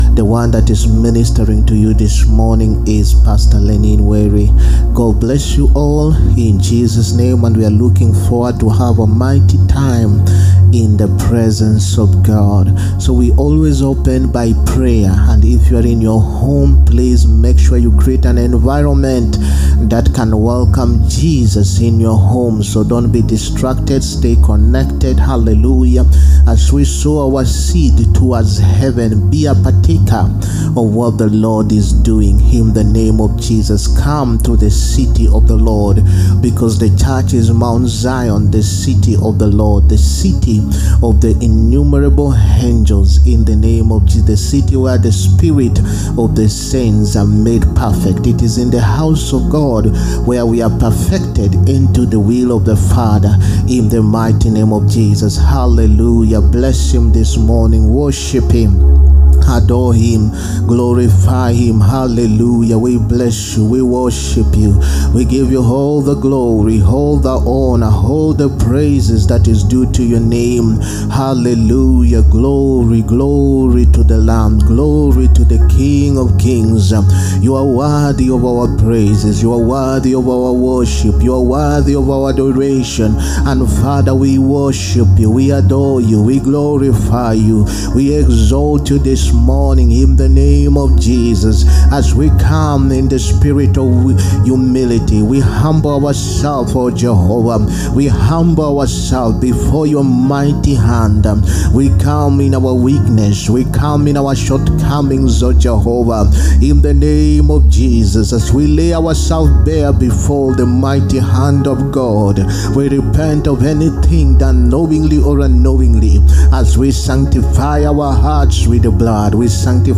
SUNDAY IMPARTATION SERVICE. 21ST JULY 2024. PART 1.